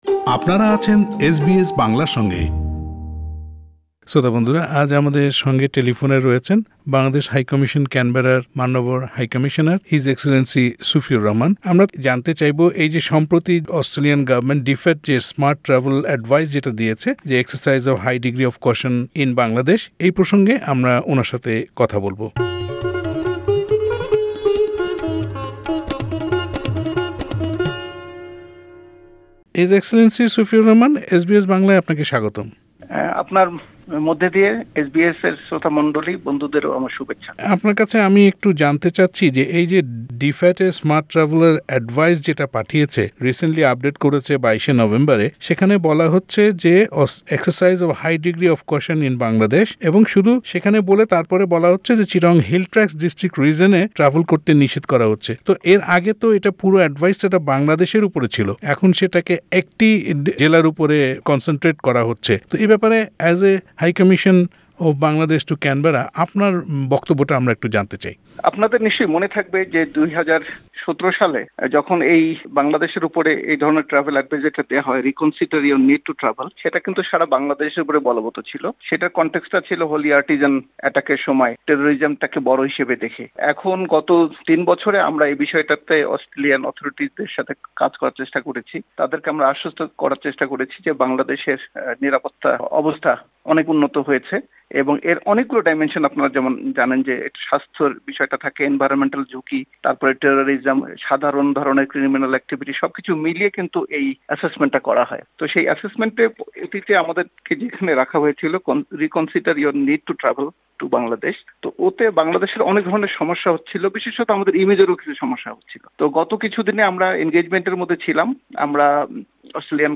এসব বিষয় নিয়ে এসবিএস বাংলার সঙ্গে কথা বলেছেন অস্ট্রেলিয়ায় নিযুক্ত বাংলাদেশের হাই কমিশনার মান্যবর মোহাম্মদ সুফিউর রহমান।